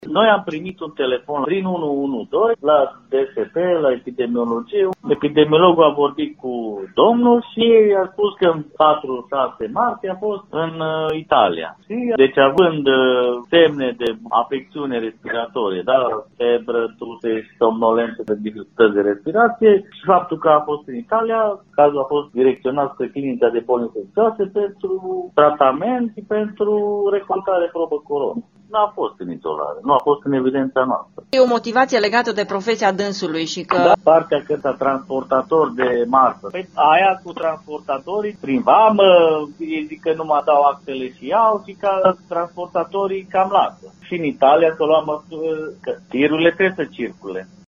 Acesta este din Lechința-Iernut și a ajuns la spital la Târgu-Mureș după ce a acuzat simptome specifice bolii, a precizat pentru Radio Târgu-Mureș directorul DSP Mureș, dr. Iuliu Moldovan: